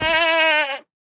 sounds / mob / goat / death2.ogg